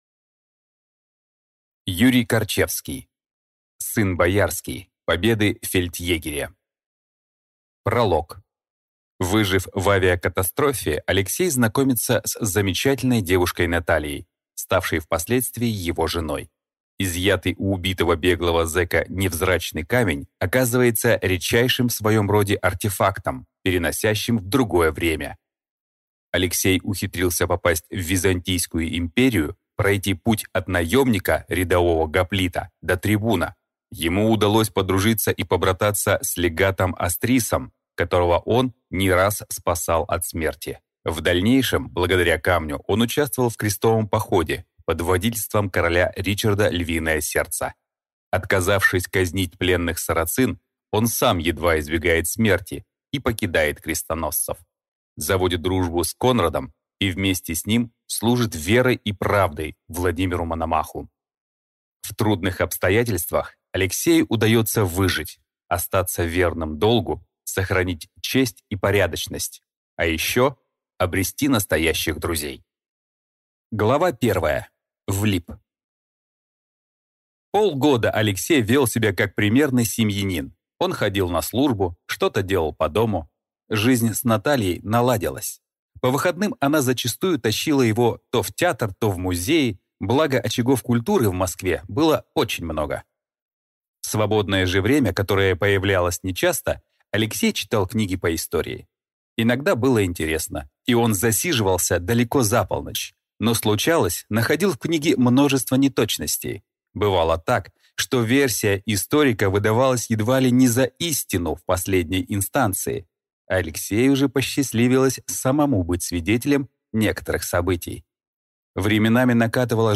Аудиокнига Сын боярский. Победы фельдъегеря | Библиотека аудиокниг